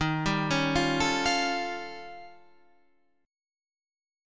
Eb7#11 chord